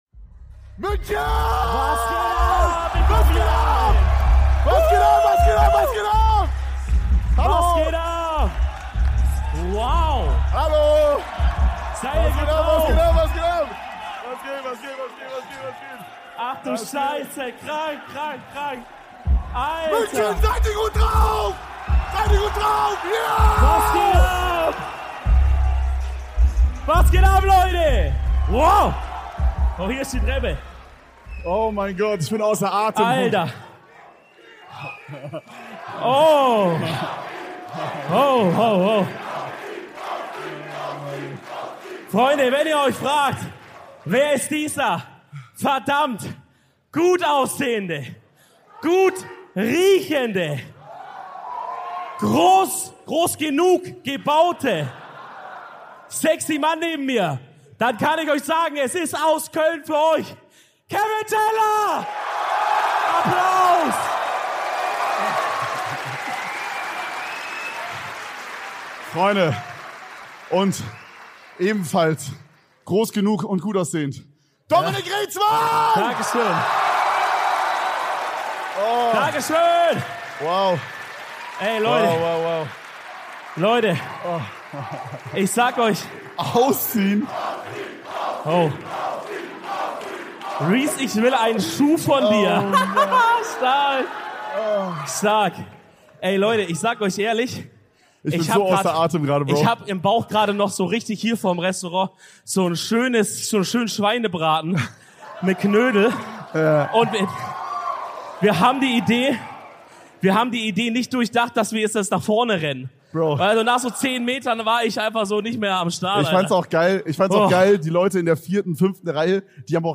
Zum letzten Mal sind wir heute live auf der Bühne und bringen unsere EDELTALK Tour 2023 zu einem würdigen Ende.